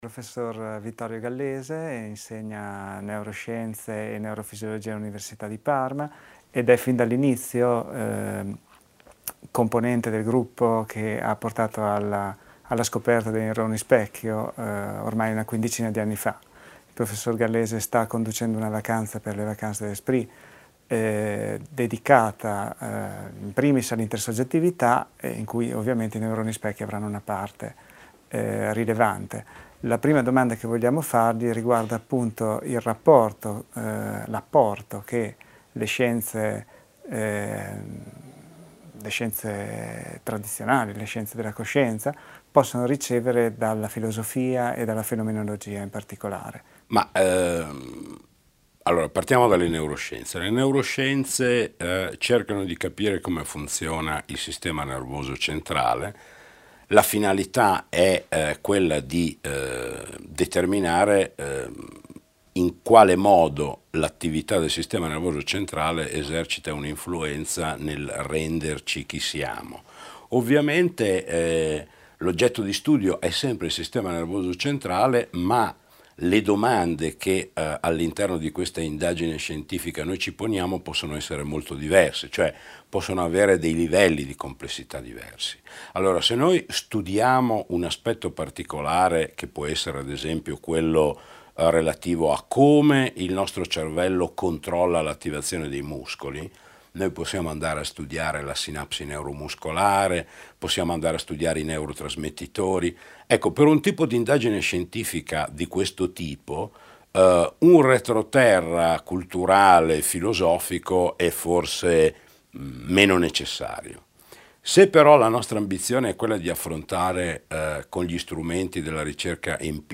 Intervista a Vittorio Gallese alle Vacances de l'Esprit 2007